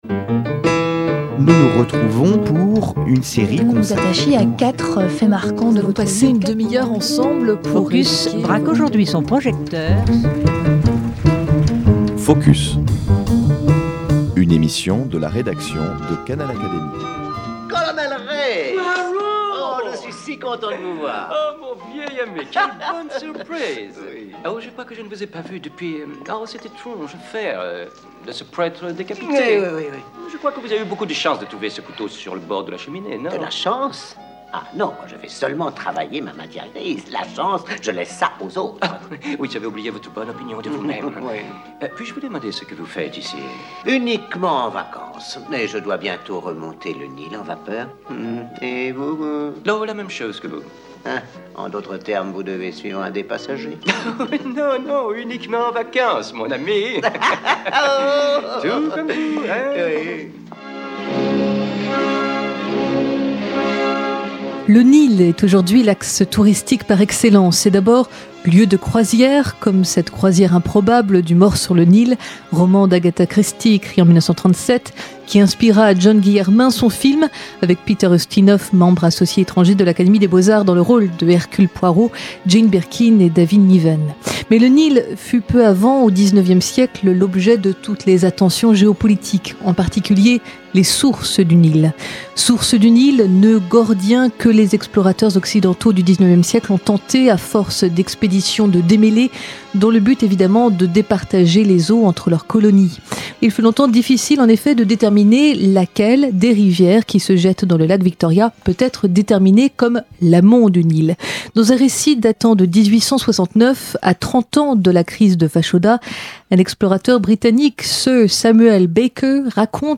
Montez à bord de notre felouque, et descendez le Nil, depuis ses sources au Burundi et en Ethiopie, jusqu’au Delta. Ecoutez les récits des explorateurs Sir Samuel Baker et Charles Didier, les écrits de Roger Luzarche d’Azay, Jean Leclant et Naguib Mahfouz.
Extraits lus